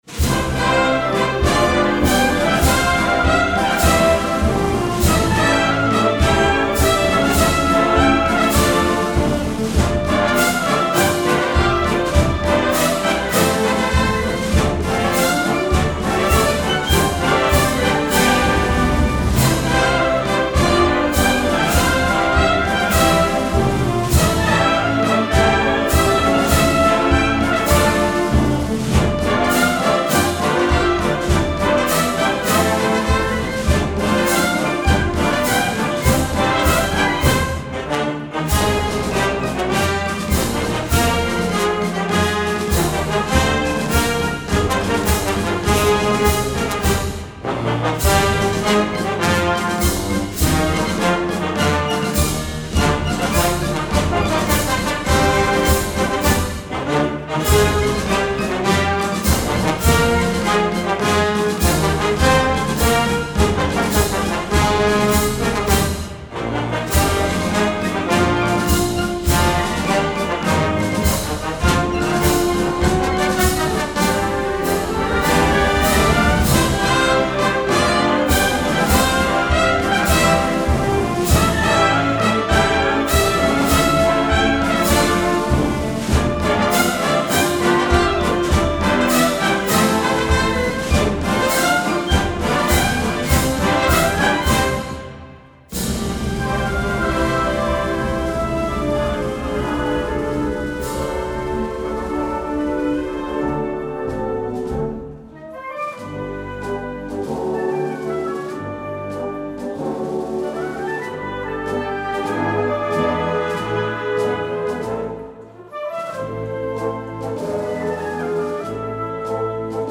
XXXIV acto de Exaltación a Nuestra Señora de la Encarnación
Con la interpretación de la marcha «
Soberana Encarnación» del compositor don Daniel Albarrán Acosta, estrenada el pasado día 26 de febrero en el Teatro Hogar Virgen de los Reyes, por la Banda Municipal de la Puebla del Río, dio comienzo el acto de exaltar, un año más, a la Reina de la “Calzá”.